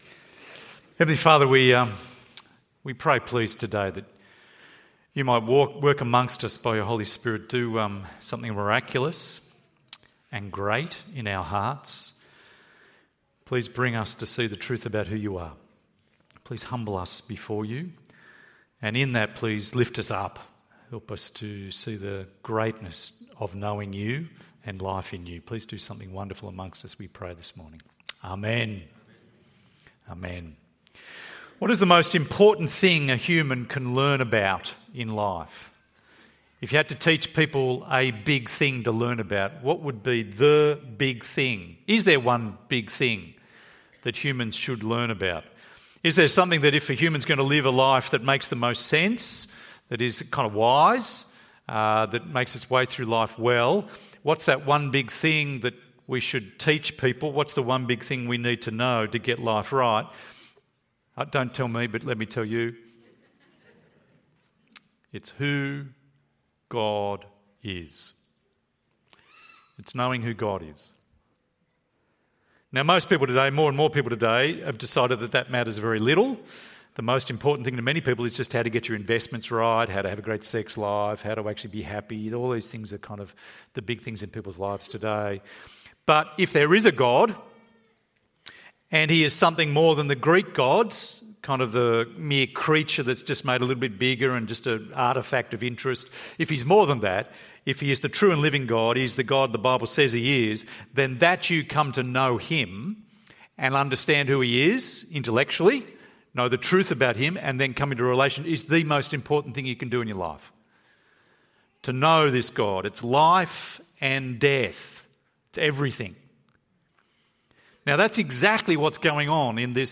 EV Church Sermons